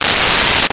Paper Tear 11
Paper Tear 11.wav